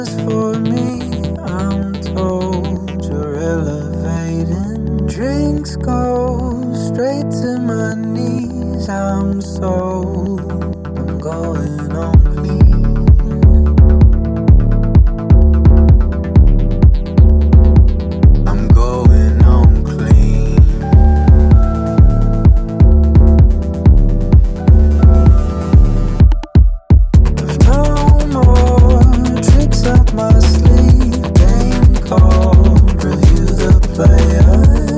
Жанр: Поп музыка / Танцевальные